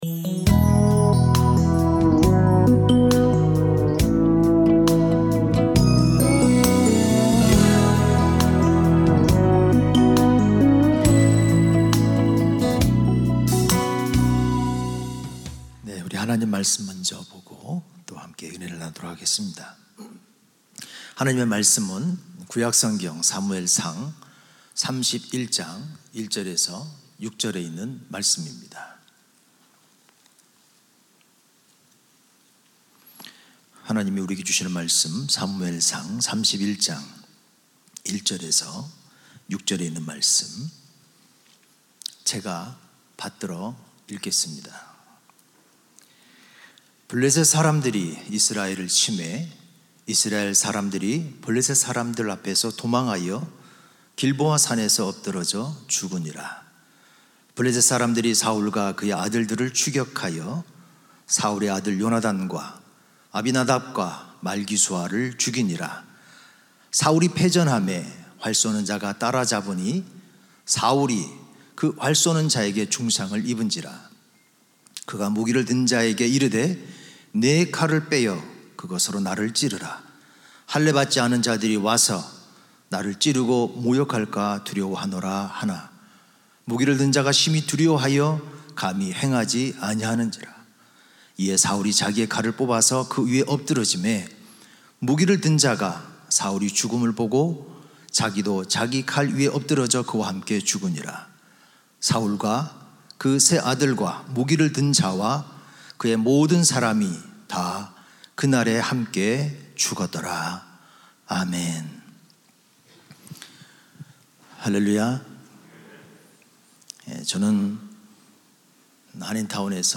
특별 새벽 집회